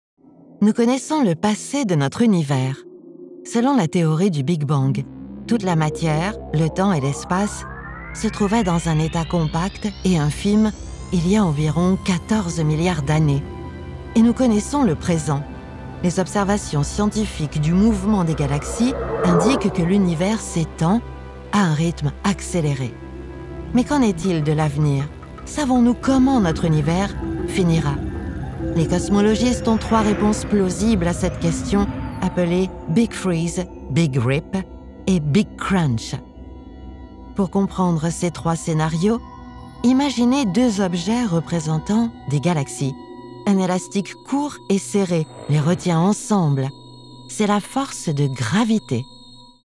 Female
Approachable, Confident, Corporate, Friendly, Reassuring, Versatile, Warm
Corporate-Toyota.mp3
Microphone: Neumann TLM 103
Audio equipment: RME Fireface UC, separate Soundproof whisper room